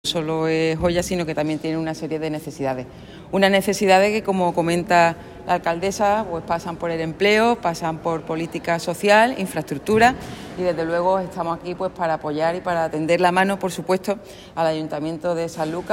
Sanlucar-Almudena.mp3